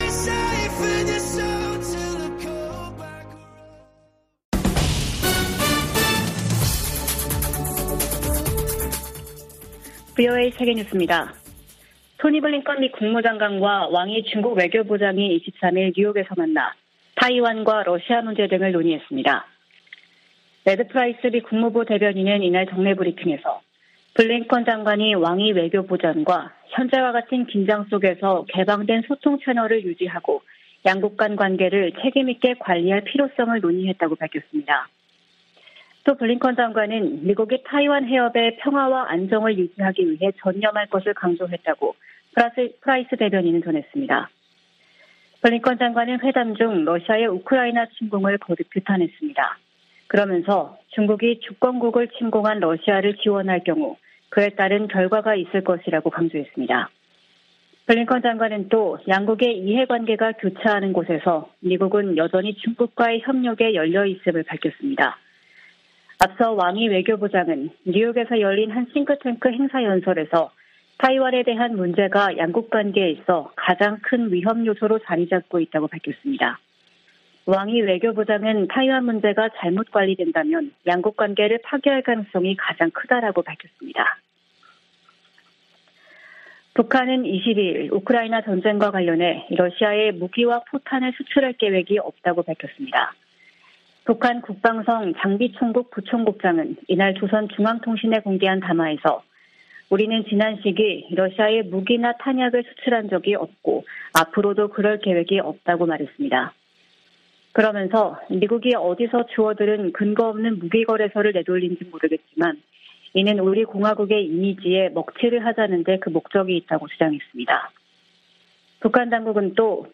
VOA 한국어 아침 뉴스 프로그램 '워싱턴 뉴스 광장' 2022년 9월 24일 방송입니다. 미 핵 추진 항공모함 로널드 레이건이 연합 훈련을 위해 부산에 입항했습니다. 미한일 외교장관들이 뉴욕에서 회담하고 북한의 핵 정책 법제화에 심각한 우려를 표시했습니다. 제이크 설리번 미국 국가안보보좌관은 북한의 7차 핵 실험 가능성이 여전하다고 밝혔습니다.